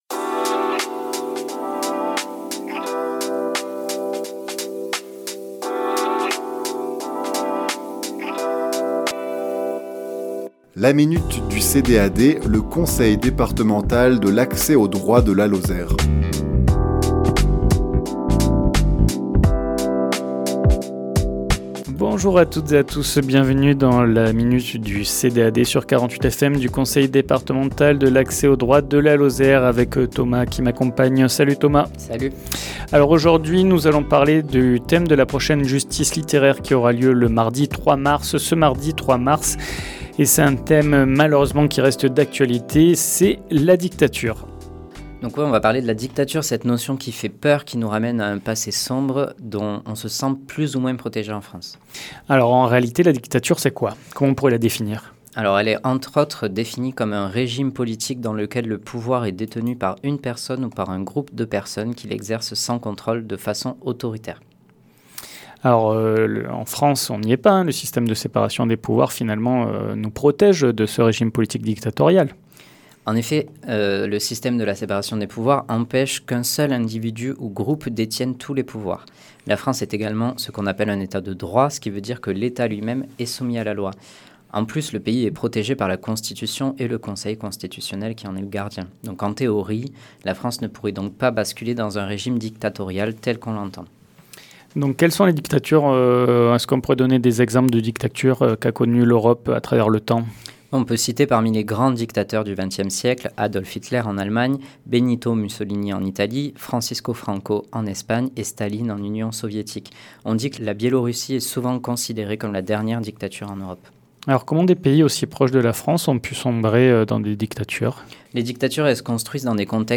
Chronique diffusée le lundi 23 février à 11h00 et 17h10